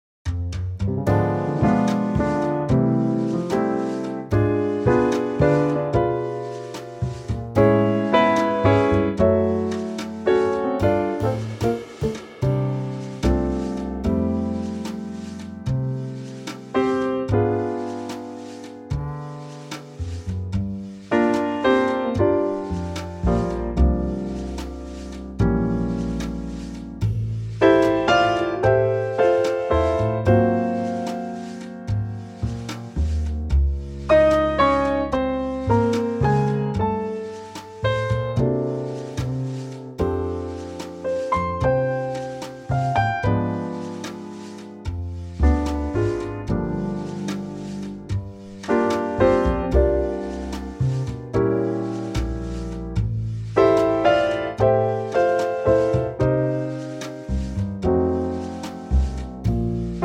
Unique Backing Tracks
key - F - vocal range - D to D
Absolutely gorgeous song in a 2025 Trio arrangement.